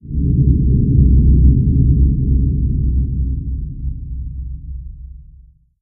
fx_drone2.ogg